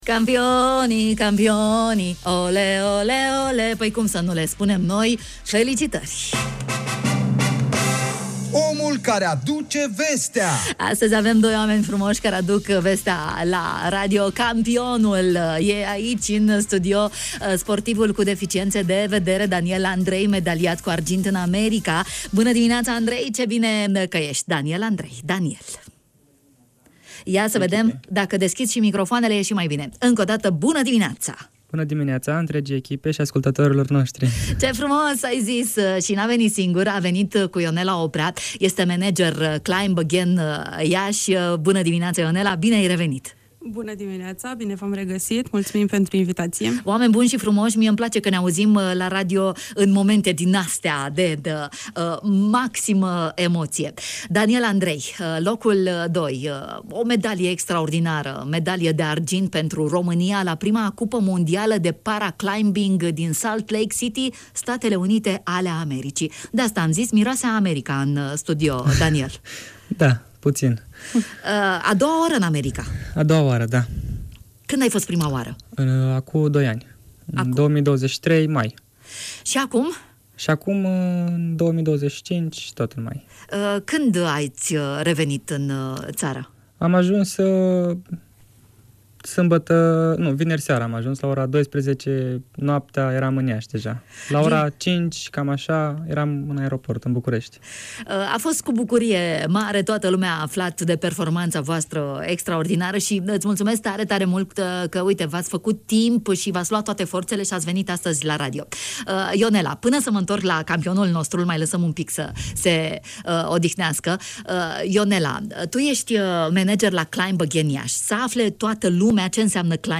Despre sutele de ore de antrenament dedicate performanței sportive, la radio, în această dimineață.